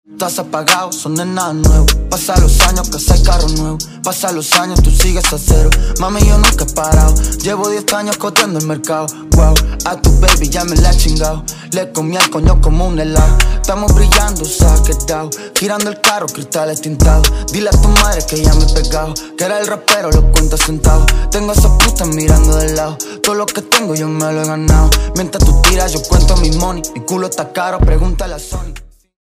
Categorie: Trap